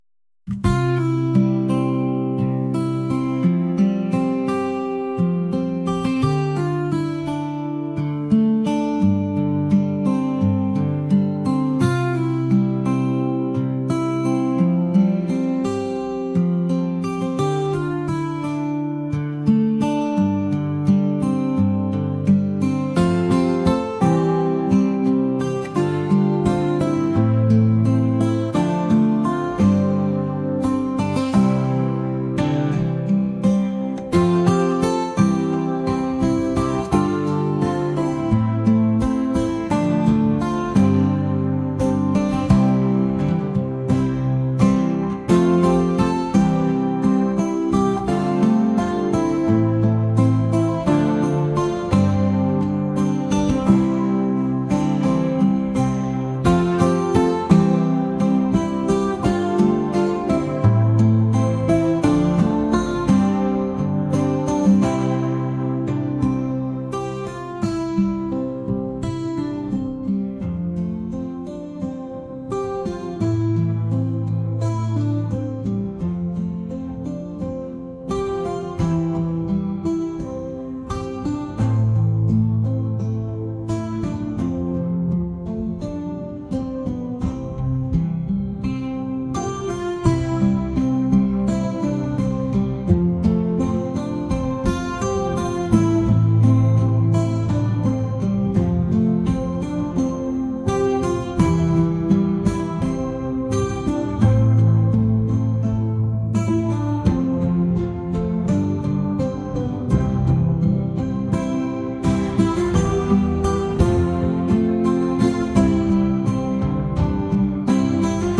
acoustic | folk | pop